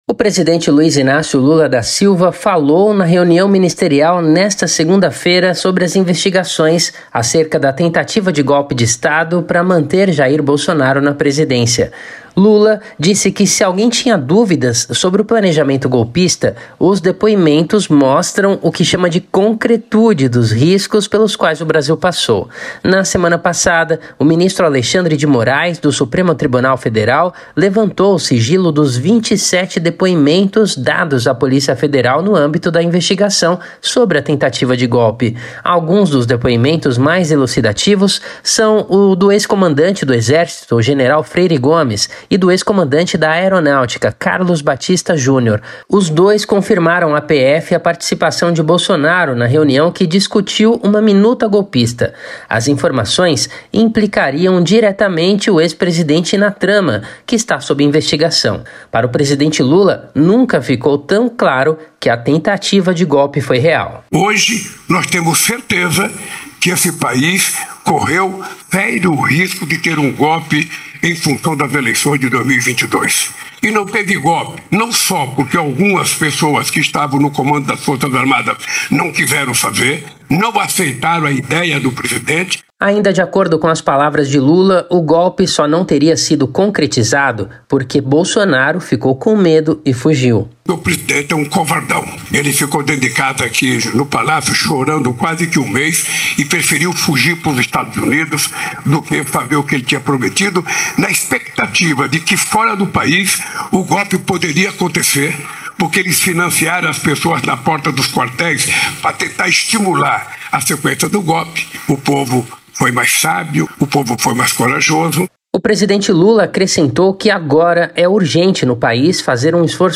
presidente Luiz Inácio Lula da Silva (PT) falou, no início da reunião ministerial, na manhã desta segunda-feira (18), sobre as investigações acerca da tentativa de golpe de Estado para manter Jair Bolsonaro (PL) na Presidência.
“Hoje nós temos mais clareza sobre o 8 de janeiro por depoimentos de gente que fazia parte do governo ou que estava no comando das Forças Armadas. Se há três meses, quando a gente falava que o golpe parecia insinuação, a gente tem certeza hoje que esse país teve riscos sérios de um golpe”, disse Lula antes da reunião ministerial à imprensa.